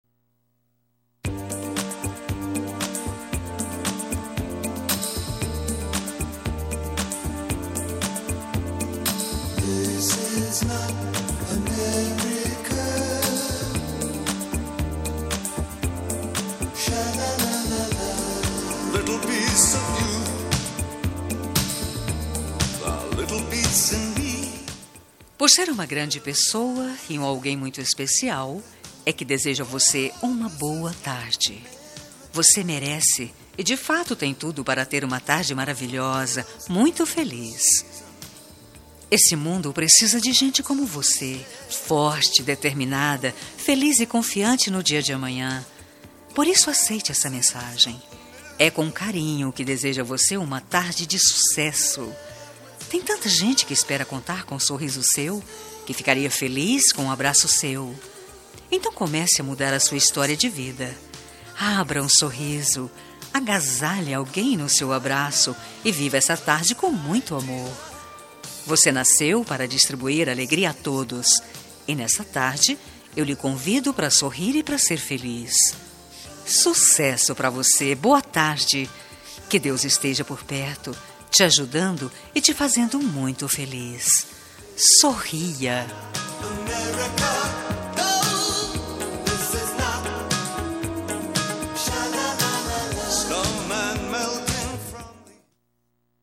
Telemensagem de Boa Noite – Voz Feminina – Cód: 6306 – Neutra
6306-b-noite-neutra-fem.mp3